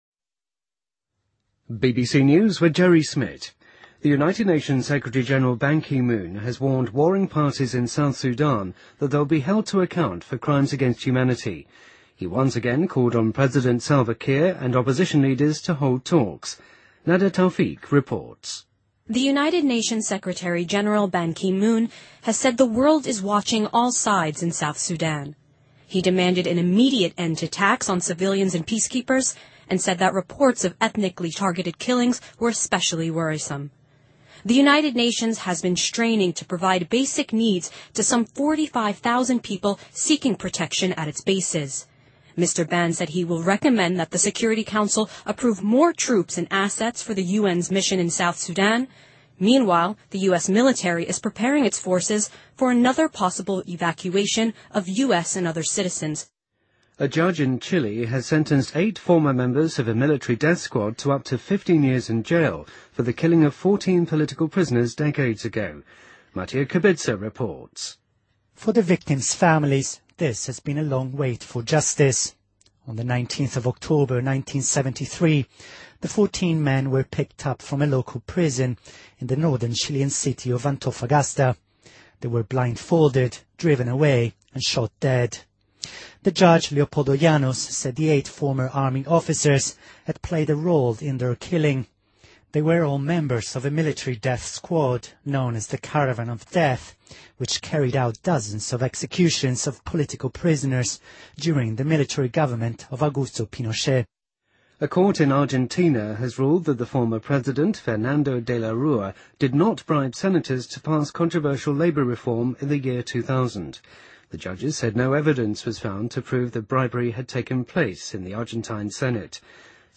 BBC news,联合国秘书长呼吁南苏丹交战双方谈判